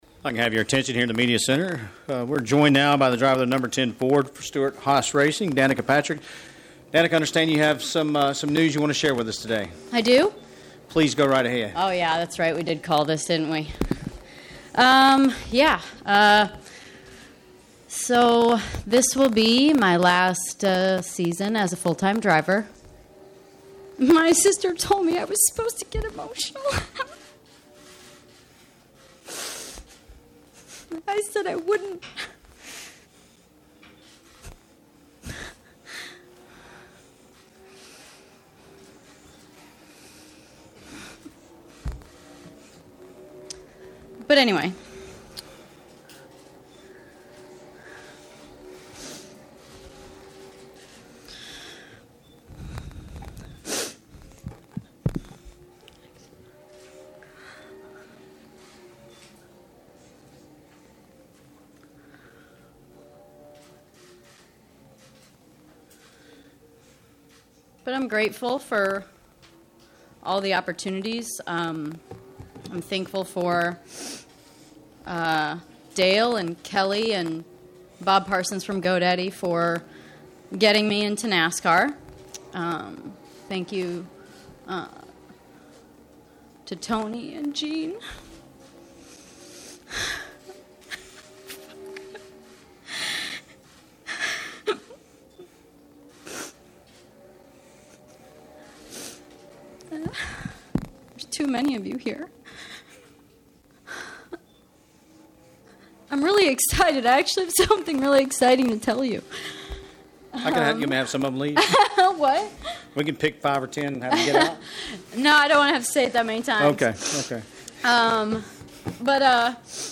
HOMESTEAD, Fla. — Danica Patrick said good-bye to racing during a tearful press conference on Friday at Homestead-Miami Speedway—but it will be a long good-bye.
Danica Patrick, driver of the #10 Aspen Dental Ford, speaks during a press conference announcing her retirement from full-time racing at Homestead-Miami Speedway on November 17, 2017 in Homestead, Florida.